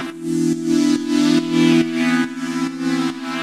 GnS_Pad-alesis1:4_140-E.wav